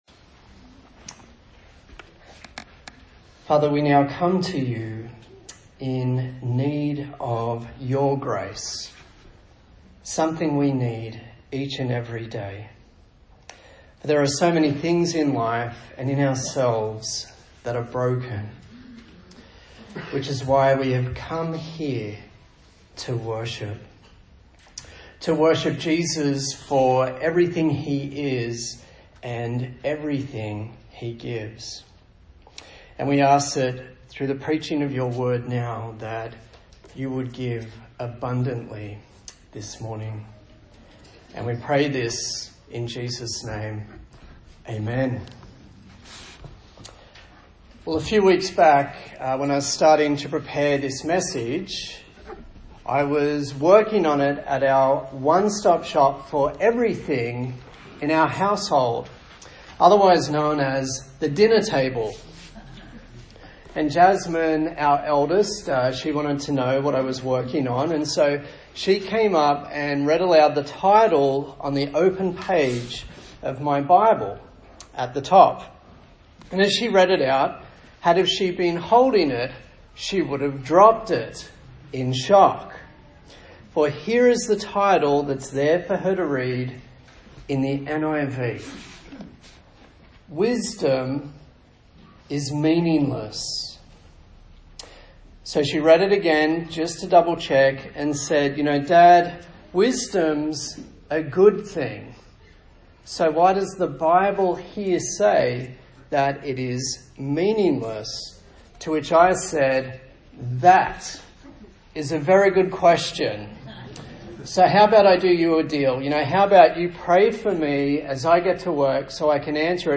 A sermon in the series on the book of Ecclesiastes
Service Type: Sunday Morning